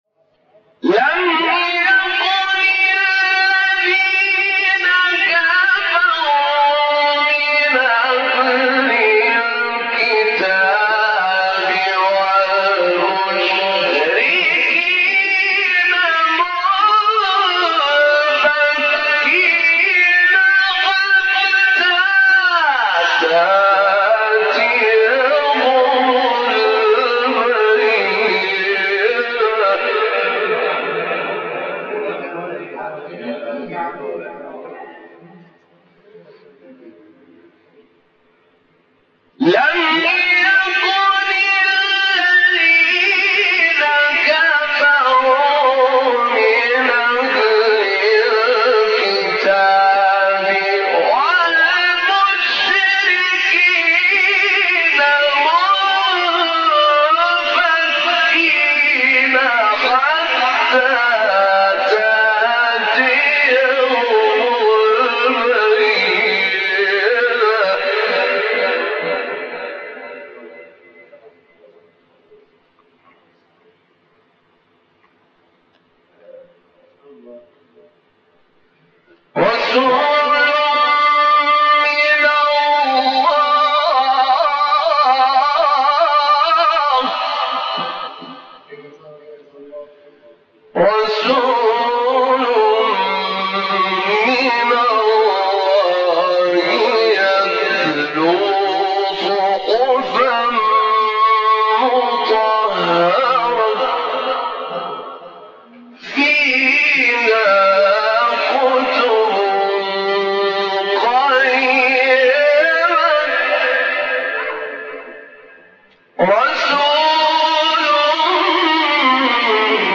سوره : بینه آیه : 1-3 استاد : عبدالفتاح طاروطی مقام : رست قبلی بعدی